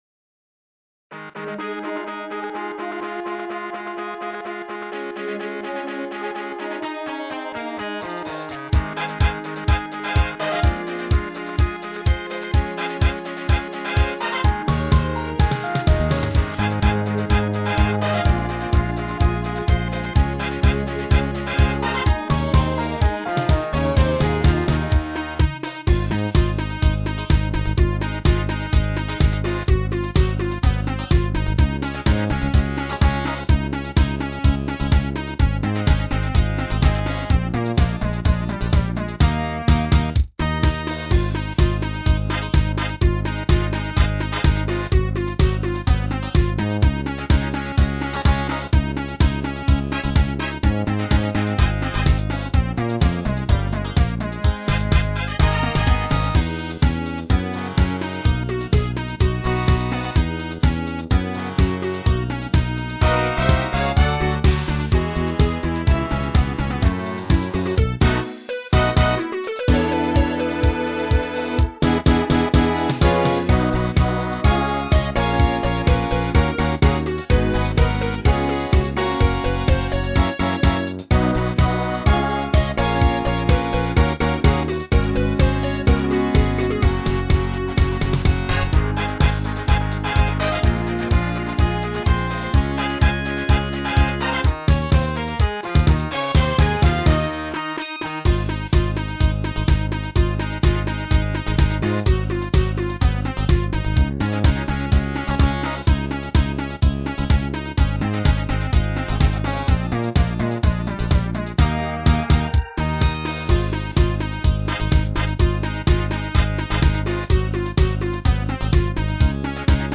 ギターもピッチベンドは使わずスラーとオクターブ奏法で逃げた。
逆にこの方がWindouws音源ではましかも。